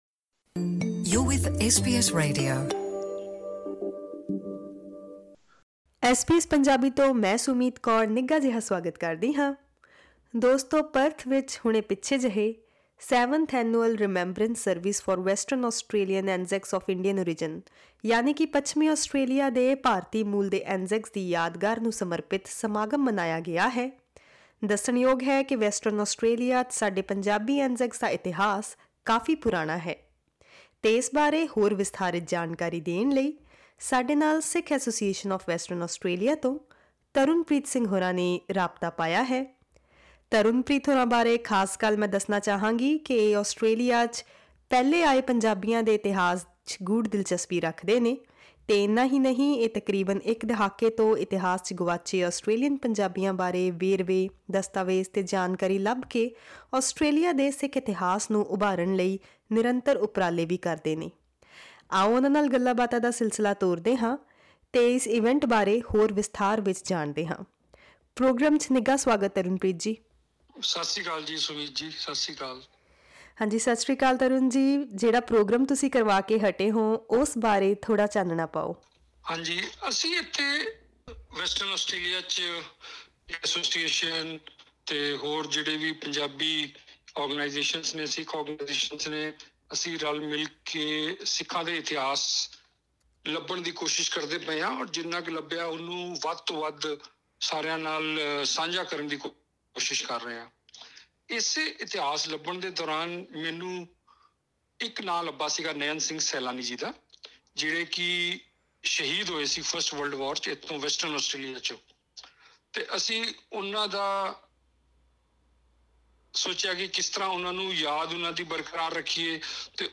SBS Punjabi